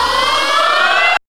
1806R SYN-FX.wav